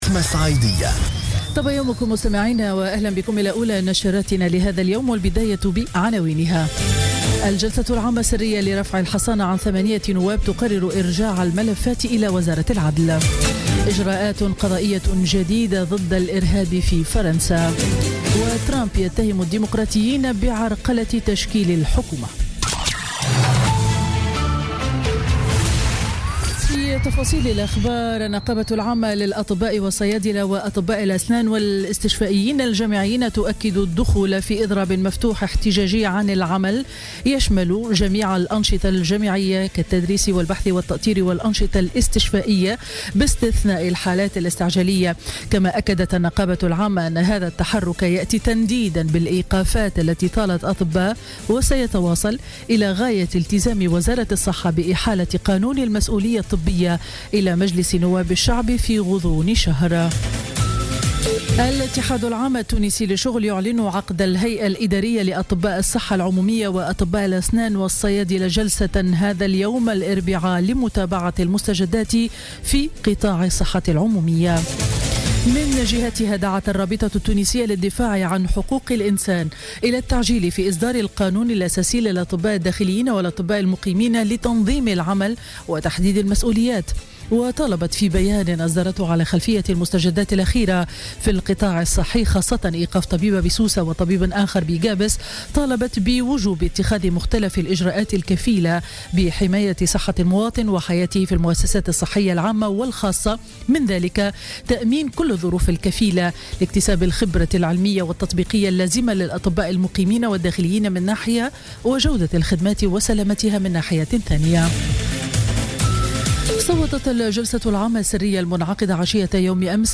Journal Info 07h00 du mercredi 8 fevrier 2017